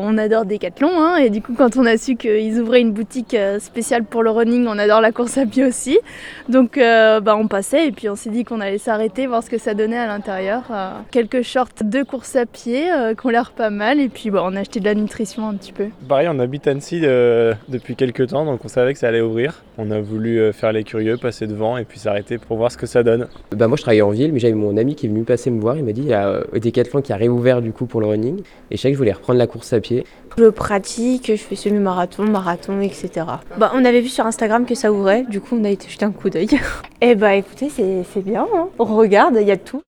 Dès l’ouverture jeudi dernier, les premiers clients n’ont pas perdu une seconde pour venir découvrir l’enseigne.
Ils expliquent la raison de leur présence dès le premier jour d’ouverture.